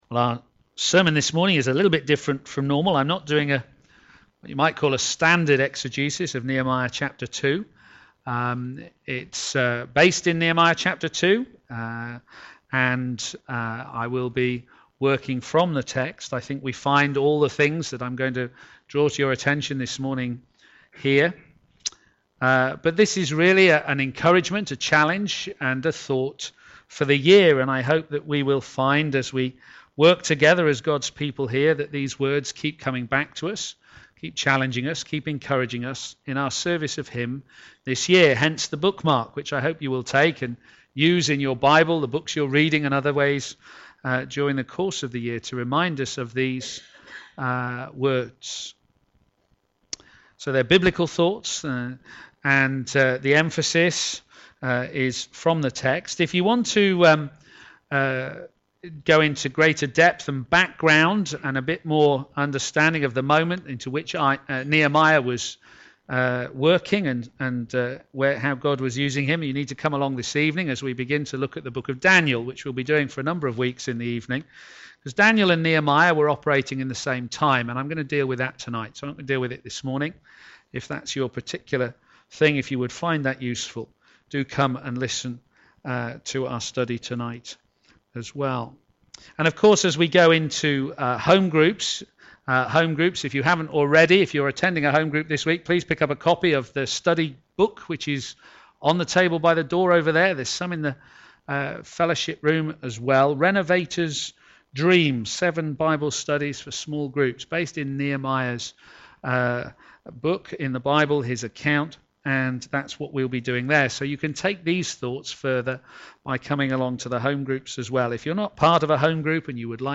What Way? What Wall? Sermon